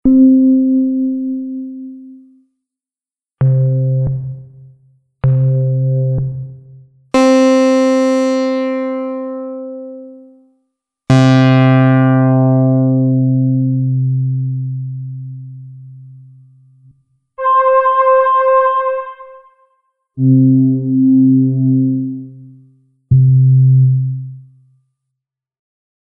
The Poly-61 is a classic 1980’s Analogue synthesiser from Korg.
113 – Polysix Electric Piano
116 – Polysix Harpsichord
Due to architectural differences between the Polysix and the Poly-61, some patches will sound quite different to the originals and some incredibly similar “demo below”.
DigiD Performances all recorded with lexicon reverb.